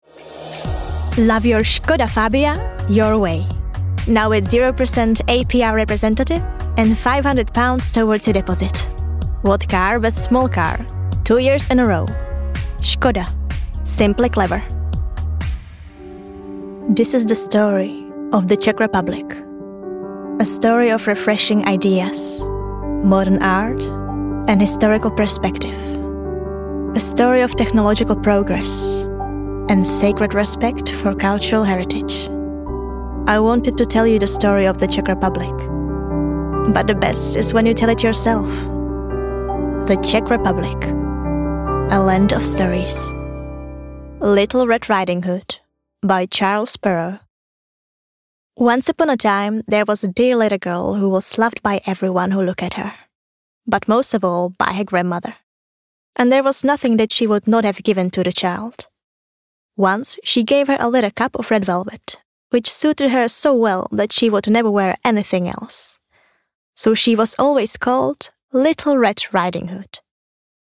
Czech, Eastern European, Female, Teens-30s